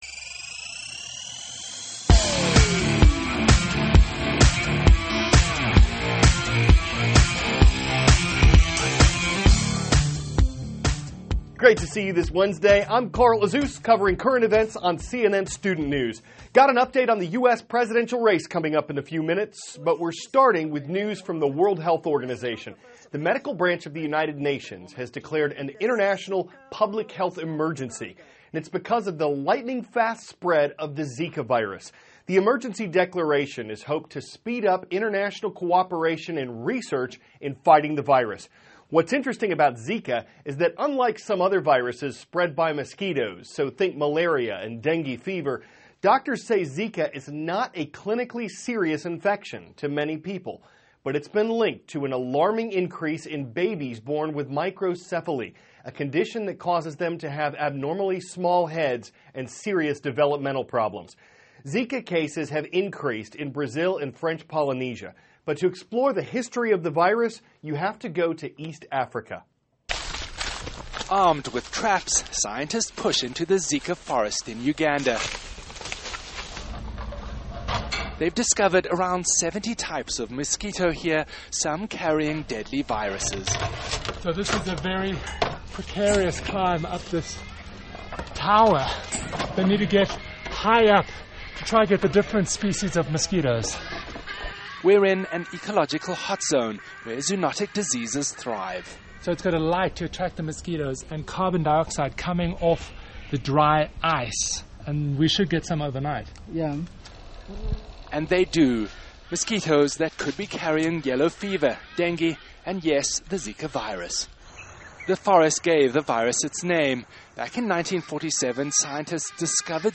(cnn Student News) -- February 3, 2016 WHO Declares International Public Health Emergency; Chinese Lunar New Year; The Results of the Iowa Caucuses THIS IS A RUSH TRANSCRIPT.